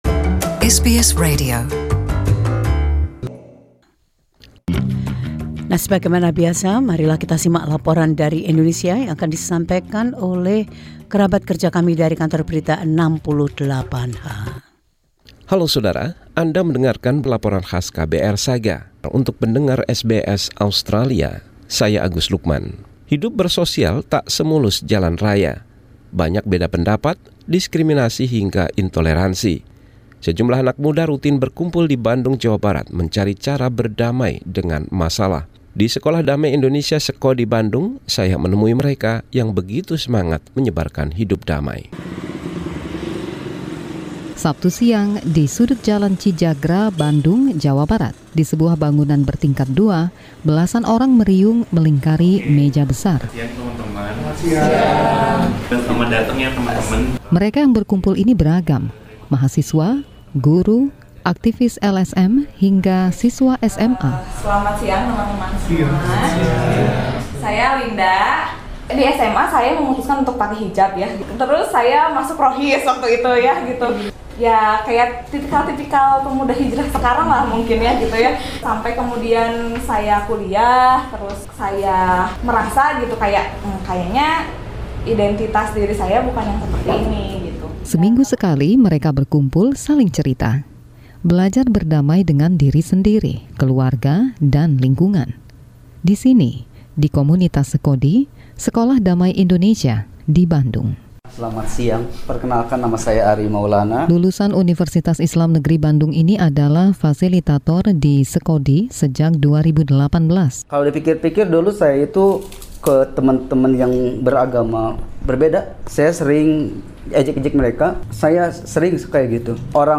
Mengapa interaksi adalah kunci untuk belajar perdamaian? Para anggota Sekodi berbagi pengalaman mereka dengan tim KBR 68H.